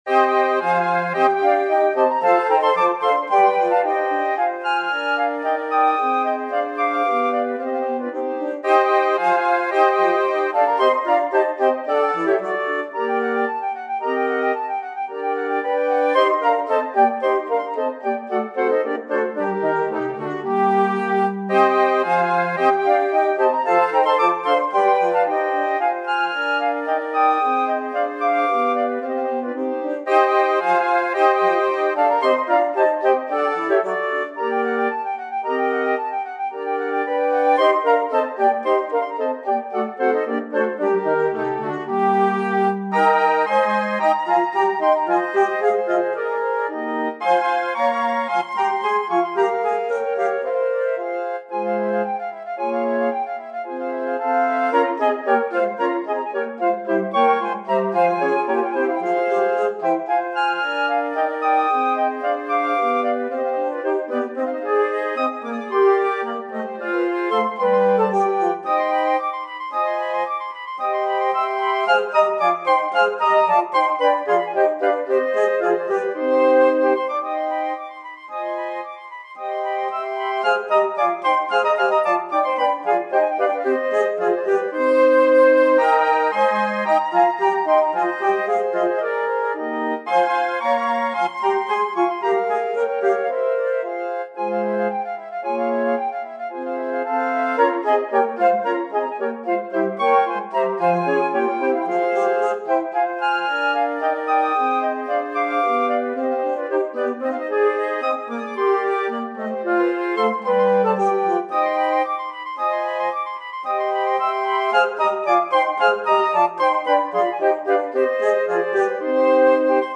Flötenquintett
• C-Besetzung: Flöte 1/2/3, Altflöte in G, Bassflöte